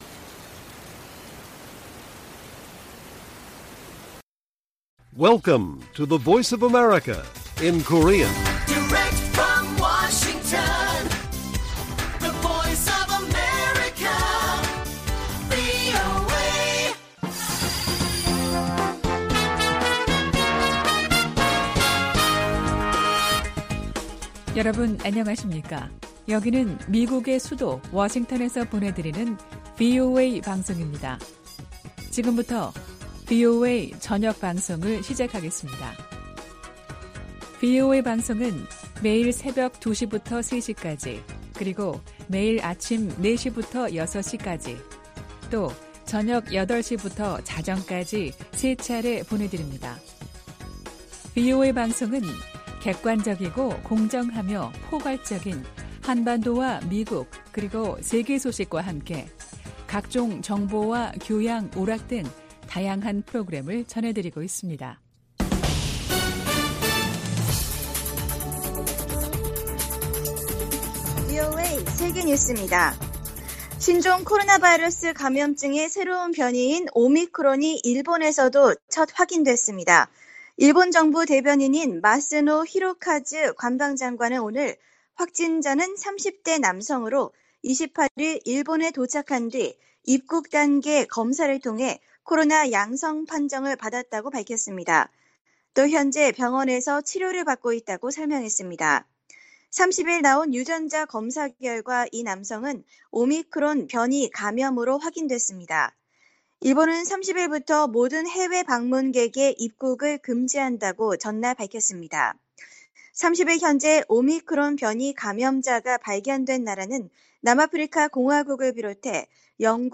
VOA 한국어 간판 뉴스 프로그램 '뉴스 투데이', 2021년 11월 30일 1부 방송입니다. 해외주둔 미군 재배치 검토를 끝낸 미국방부는 주한미군 규모를 현행수준으로 유지하기로 했습니다. 북한 선박들의 공해상 움직임이 늘고 있는 가운데 미 국무부는 국제사회의 대북제재 이행의 중요성을 거듭 강조했습니다.